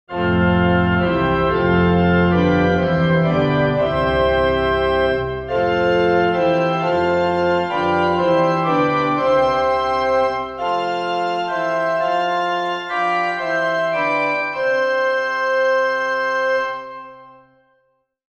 An auralization of an acoustical model of the sanctuary, using a sampled anechoic organ as the source file is provided as “organ 250 dip.wav”.
3. Organ “250 Hz dip” RT
organ-250-dip.wav